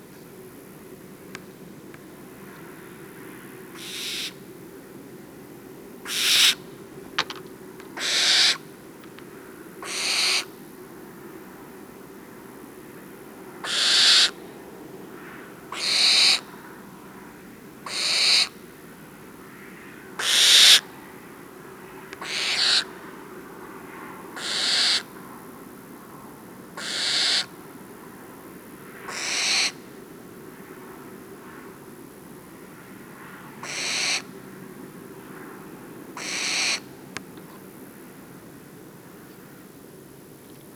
Звук голодного птенца, требующего еды